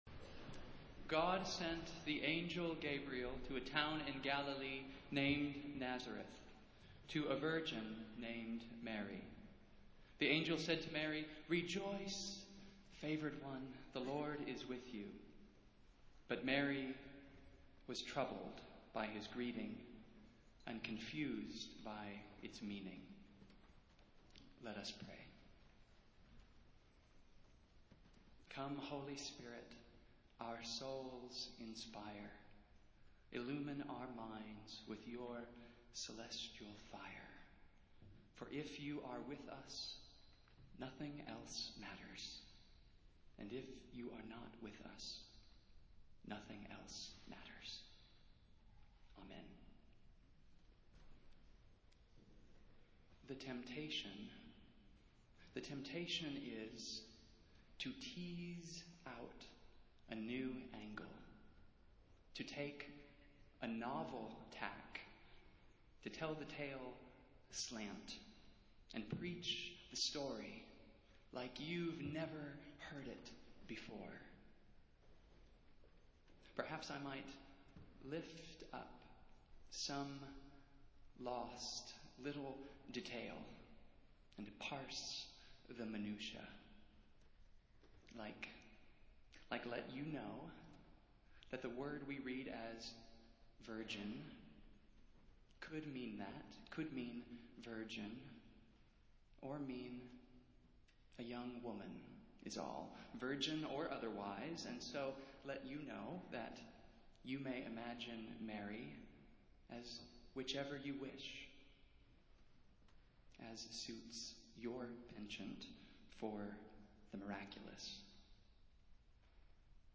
Festival Worship - Fourth Sunday in Advent